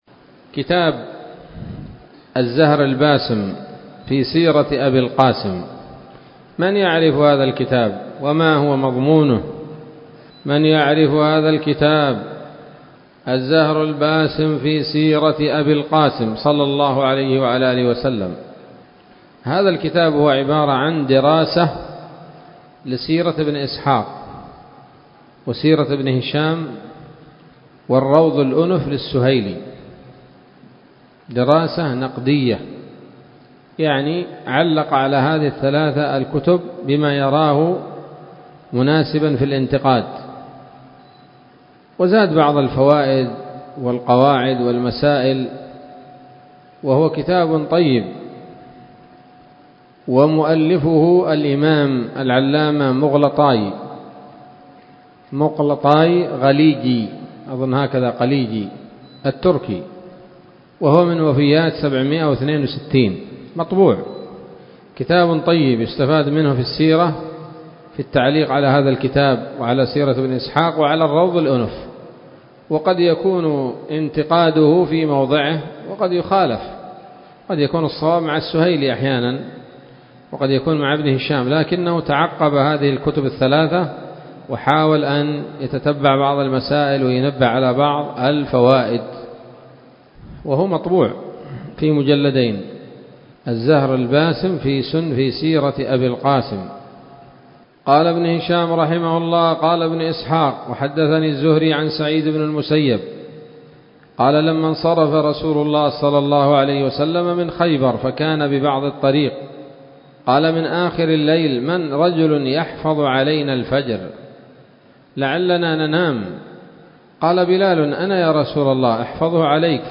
الدرس الخامس والأربعون بعد المائتين من التعليق على كتاب السيرة النبوية لابن هشام